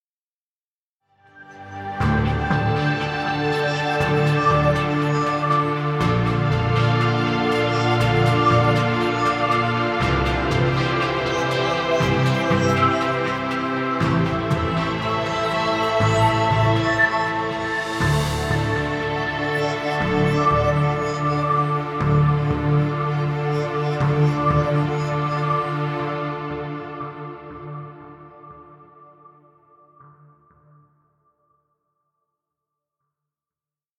Ambient music. Background music Royalty Free.